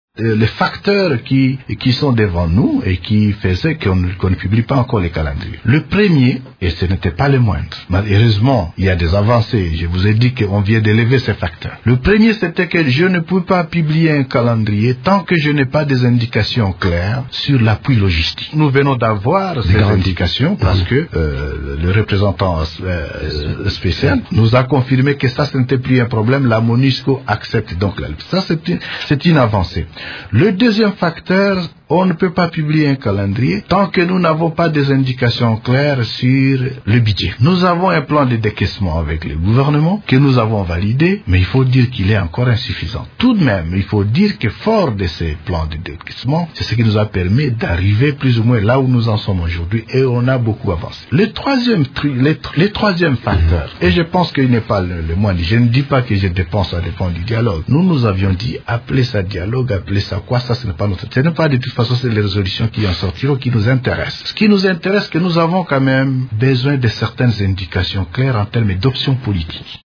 Le président de la Ceni, Corneille Nangaa lors d’une émission au studio de Radio Okapi à Kinshasa, le 30/05/2016.